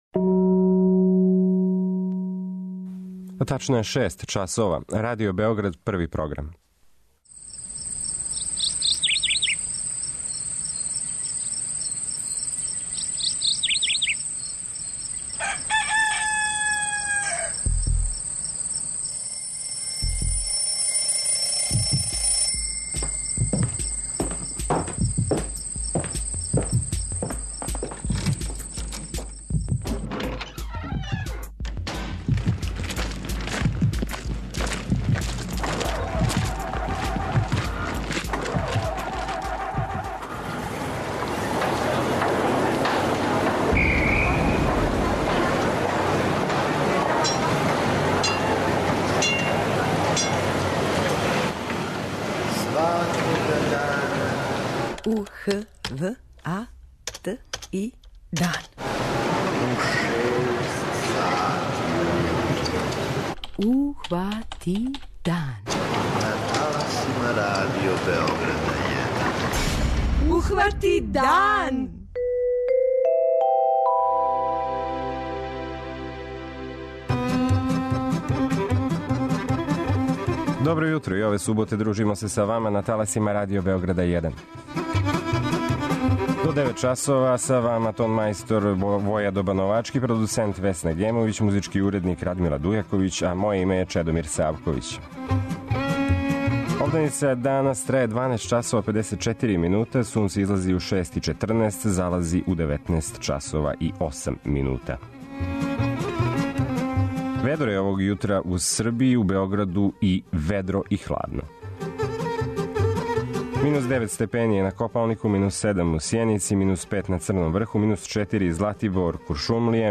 преузми : 85.96 MB Ухвати дан Autor: Група аутора Јутарњи програм Радио Београда 1!